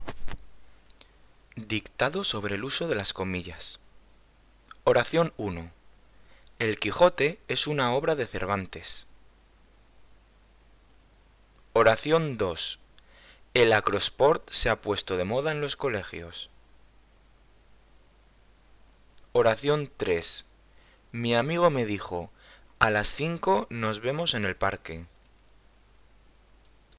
Dictado sobre el uso de las comillas (CC BY-SA)
m1_Dictado.mp3